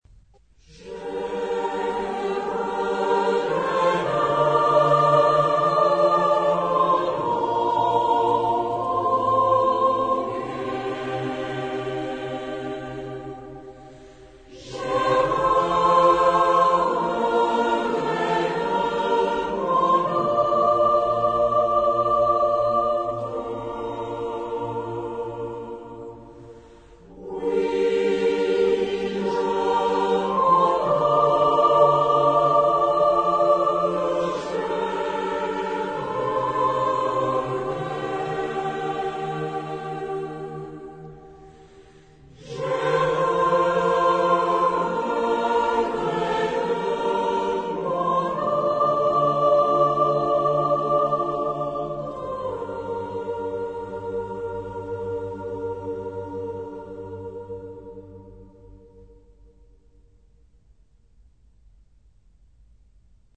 Genre-Stil-Form: Volkstümlich ; weltlich
Charakter des Stückes: langsam
Chorgattung: SATB  (4 gemischter Chor Stimmen )
Tonart(en): g-moll